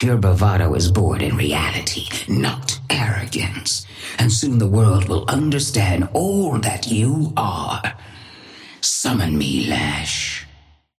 Sapphire Flame voice line - Your bravado is born in reality, not arrogance, and soon the world will understand all that you are.
Patron_female_ally_lash_start_01.mp3